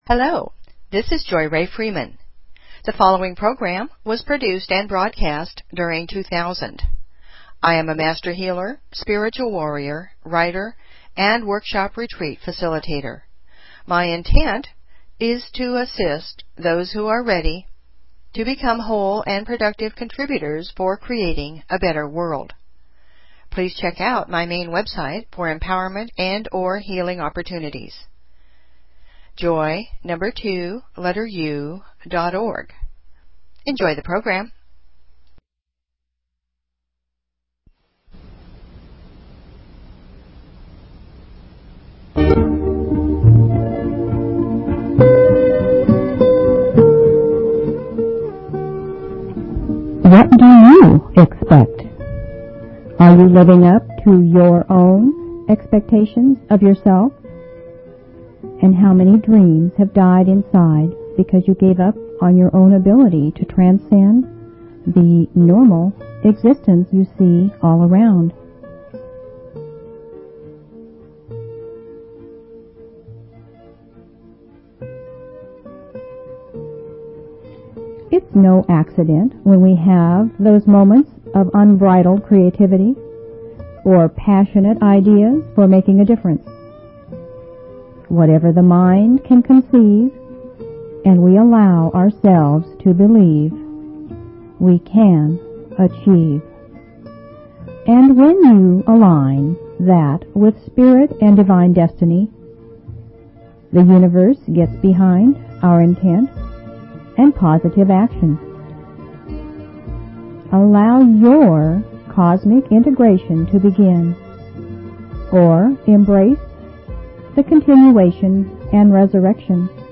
Talk Show Episode, Audio Podcast, Joy_To_The_World and Courtesy of BBS Radio on , show guests , about , categorized as
JOY TO THE WORLD - It's a potpourri of music, INSPIRATION, FOLKSY FILOSOPHY, POETRY, HUMOR, STORY TELLING and introductions to people who are making a difference. It's lively, but not rowdy - it's sometimes serious, but not stuffy - it's a little funny, but not comical - and most of all - it's a passionate, sincere sharing from my heart to yours.